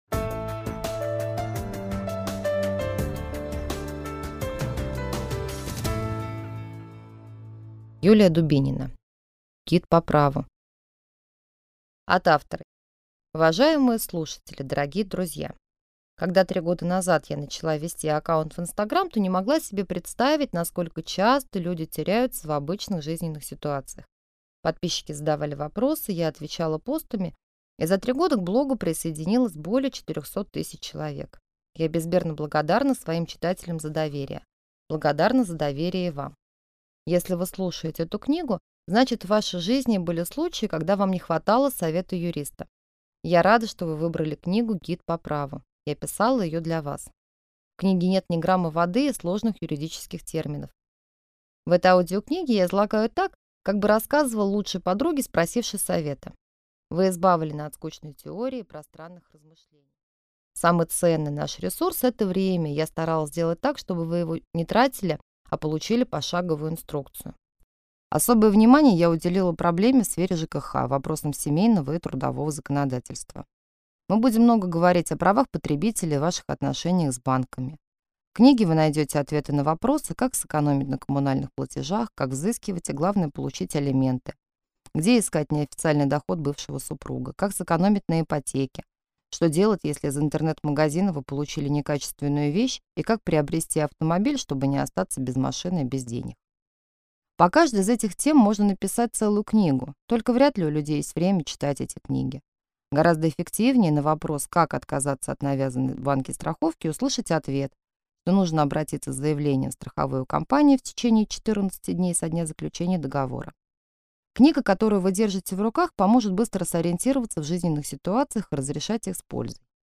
Аудиокнига Адвокат для дилетантов | Библиотека аудиокниг